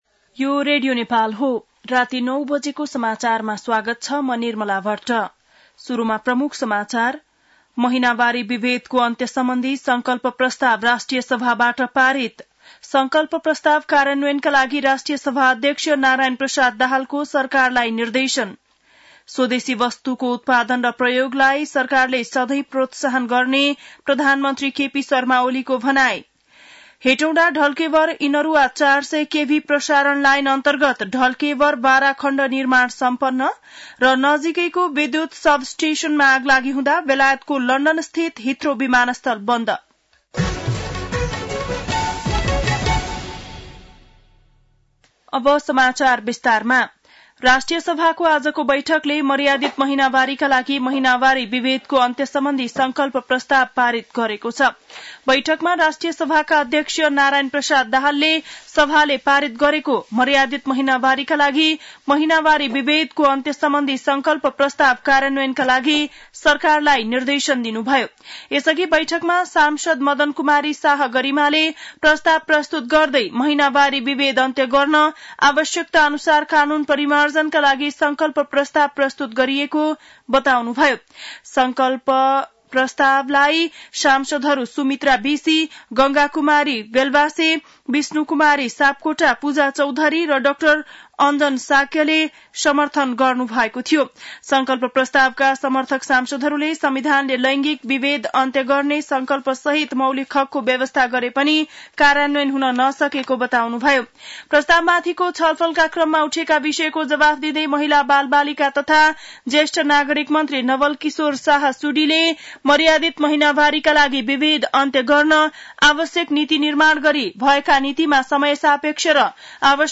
बेलुकी ९ बजेको नेपाली समाचार : ८ चैत , २०८१